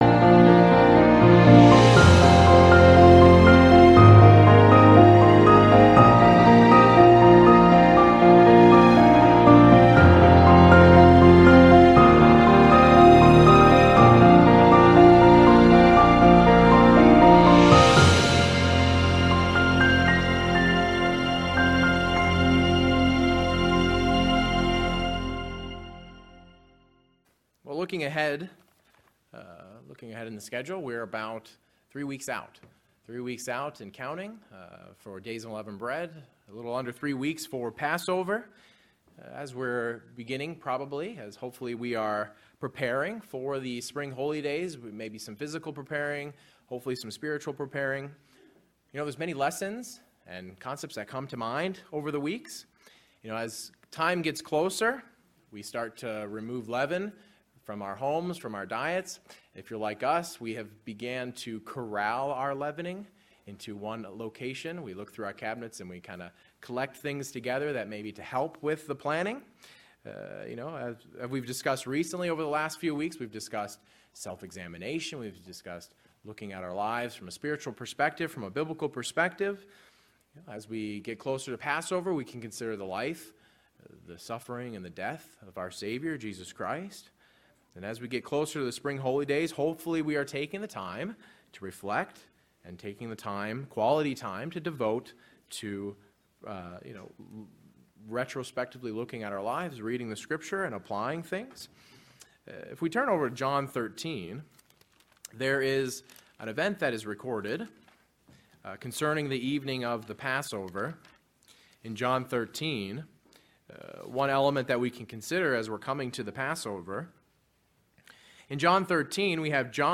For what reason are we instructed to wash one another's feet during the Passover service? This sermon examines a few spiritual lessons from the physical service of washing another's feet.